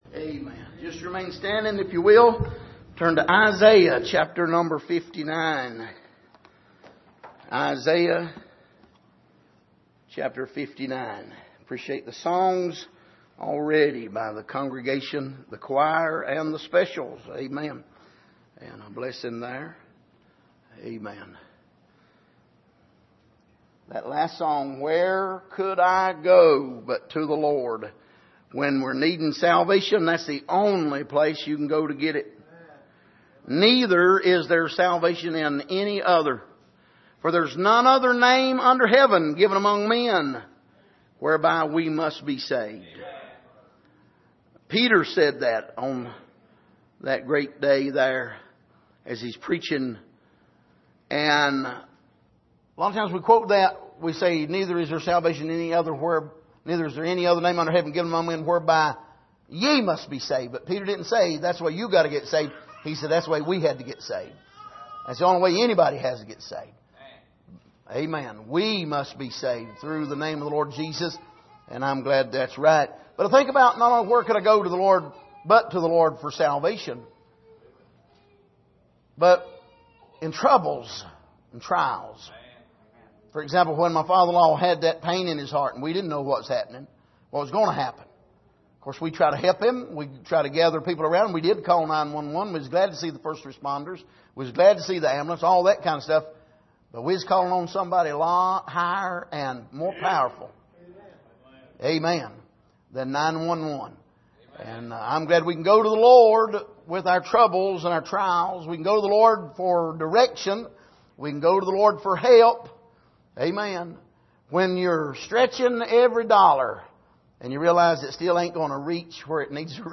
Passage: Isaiah 59:1-2 Service: Sunday Evening